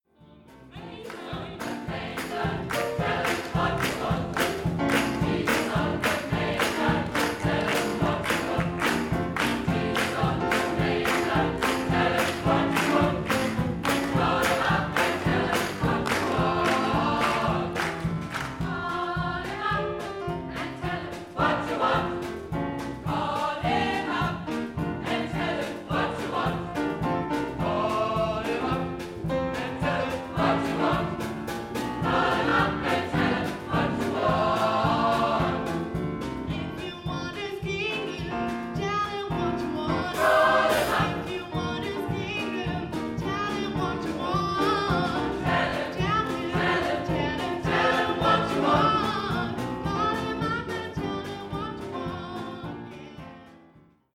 SAB, Solo + Piano